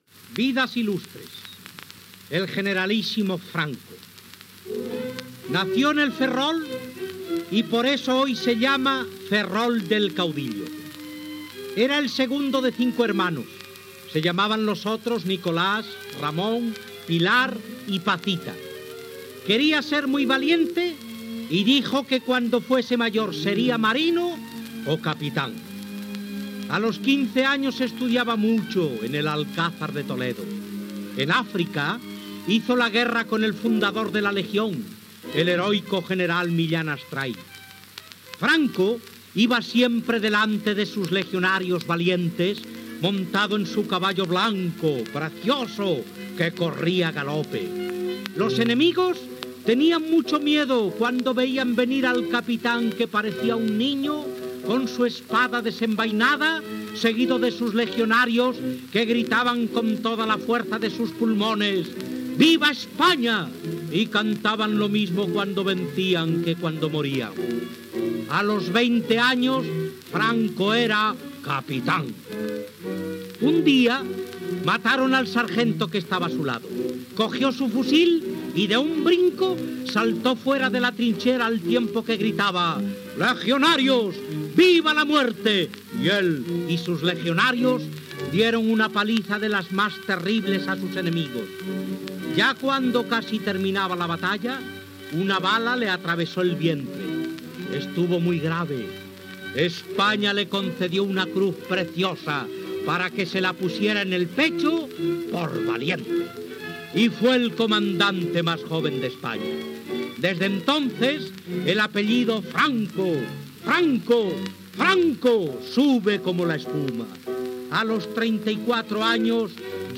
Infantil-juvenil
Presentador/a